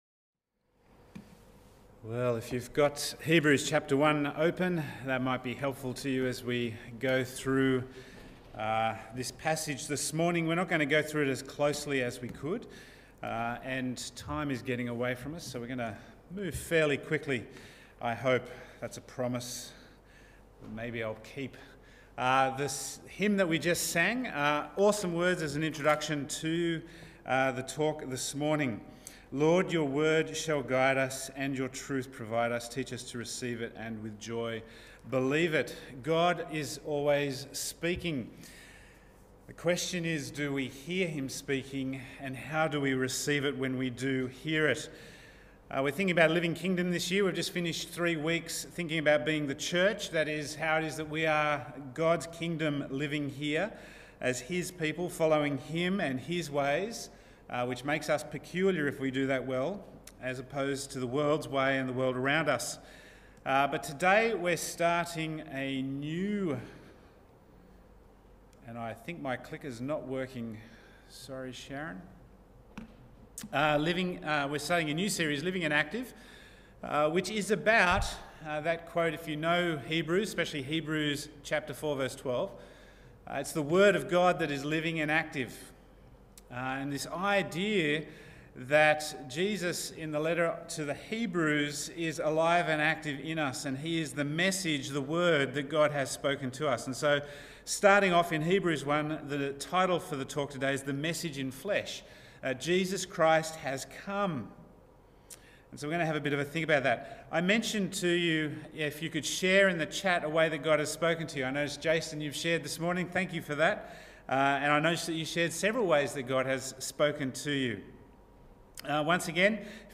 The Message in Flesh – Macquarie Chapel